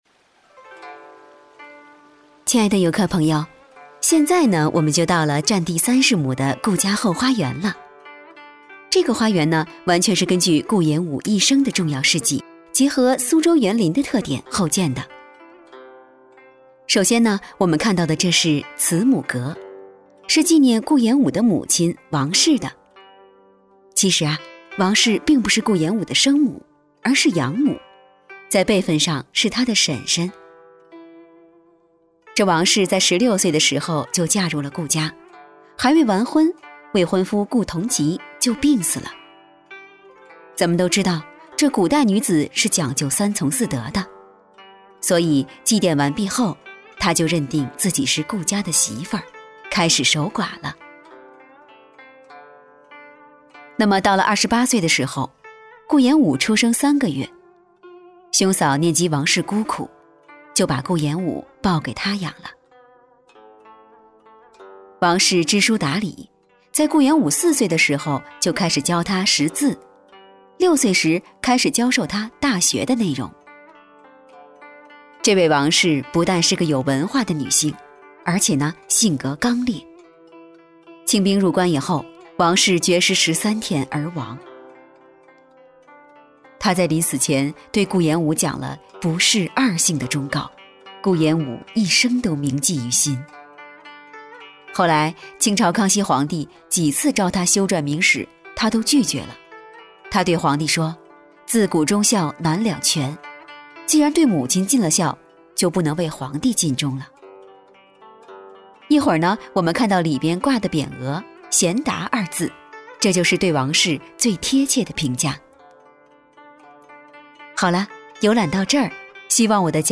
• 女S129 国语 女声 宣传片-旅游导览导游-温柔- 大气浑厚磁性|沉稳|娓娓道来|亲切甜美